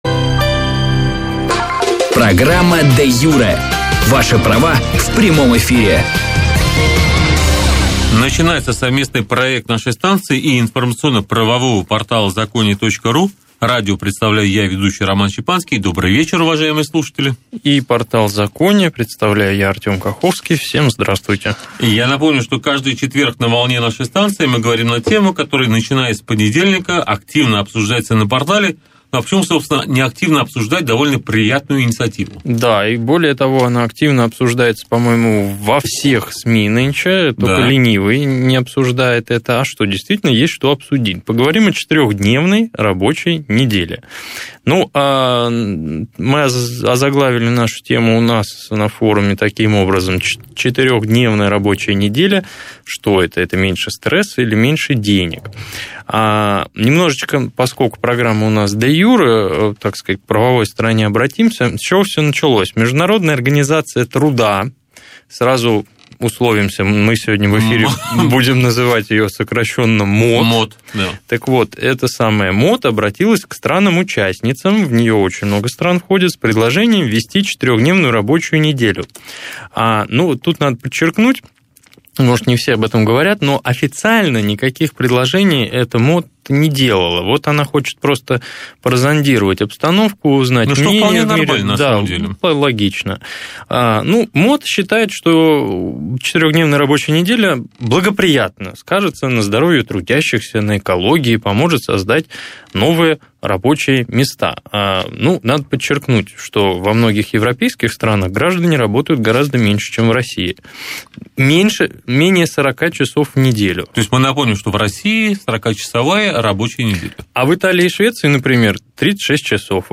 - 23.10.2014 - Главные темы и эфиры на радио | Юридический Форум Закония